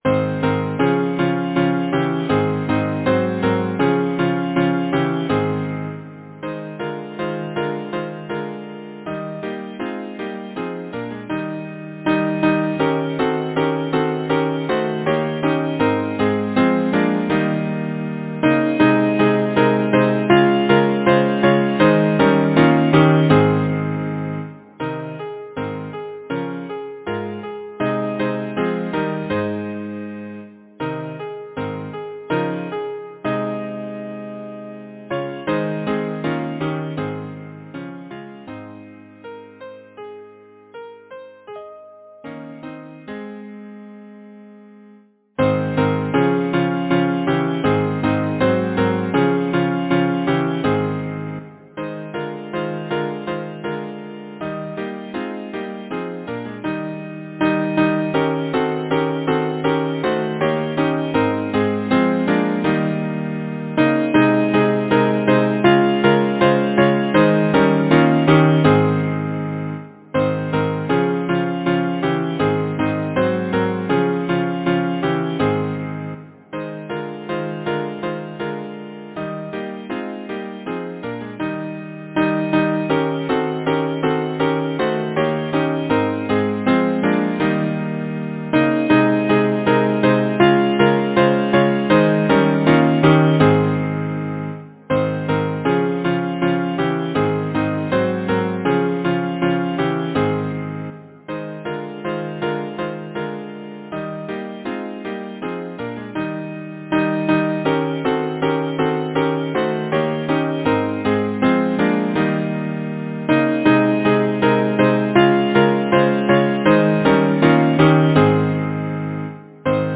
Title: The spider and the fly Composer: George Frederick Root Lyricist: Mary Howitt Number of voices: 4vv Voicing: SATB Genre: Secular, Partsong
Language: English Instruments: A cappella